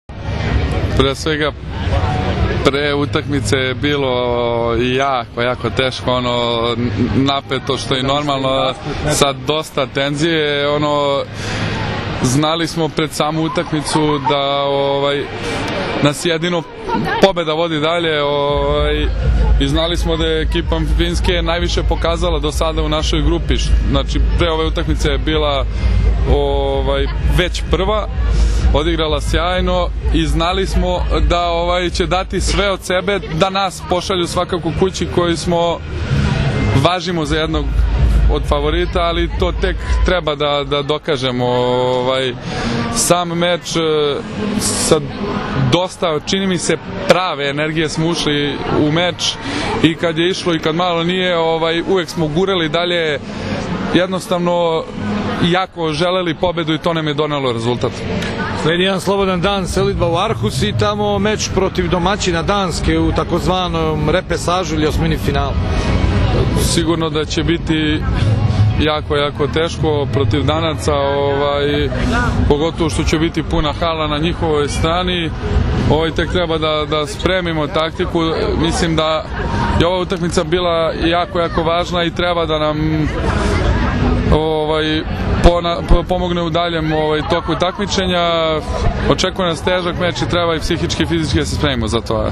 IZJAVA NIKOLE ROSIĆA